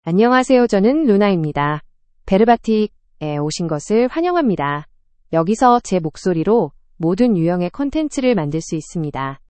LunaFemale Korean AI voice
Luna is a female AI voice for Korean (Korea).
Voice: LunaGender: FemaleLanguage: Korean (Korea)ID: luna-ko-kr
Voice sample
Listen to Luna's female Korean voice.
Luna delivers clear pronunciation with authentic Korea Korean intonation, making your content sound professionally produced.